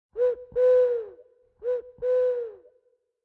Owl Humanatone Botão de Som